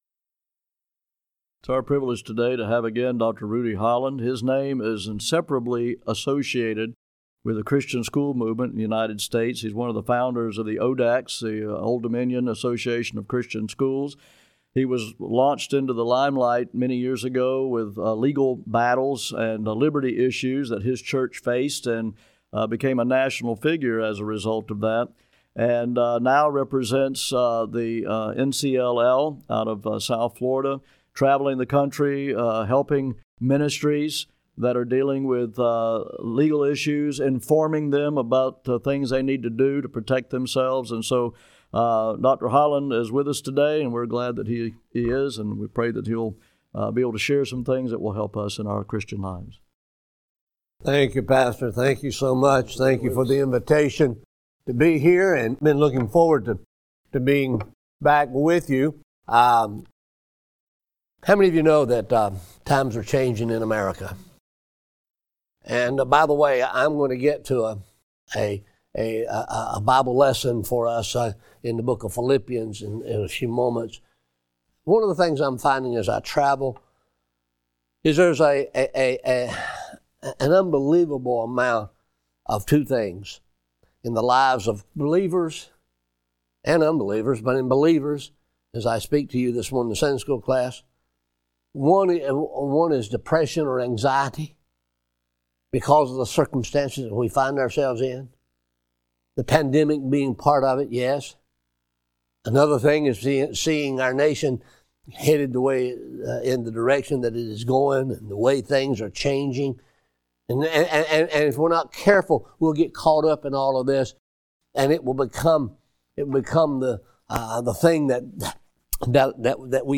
GRACE BIBLE CHURCH Audio Sermons
The preaching at Grace Bible Church in Milton, Florida is available in audio sermons on our website.